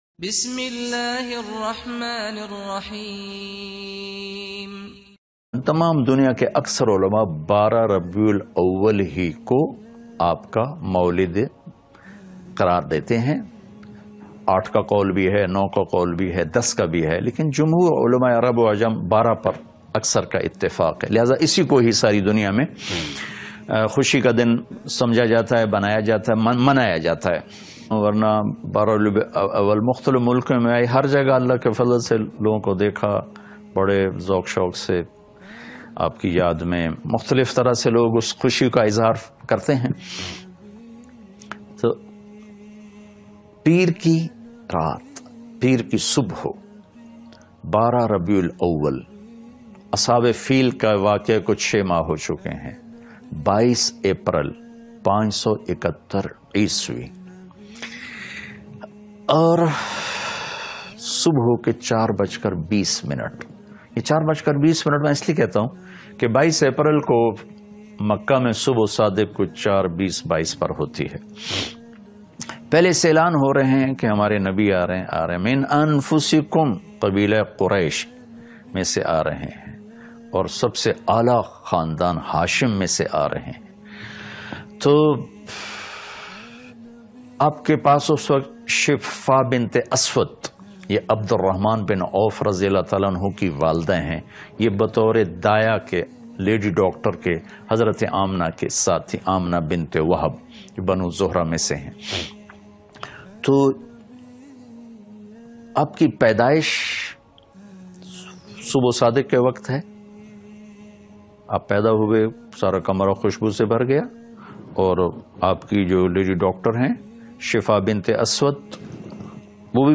12 rabi ul awal ki ahmiyat or fazilat bayan mp3.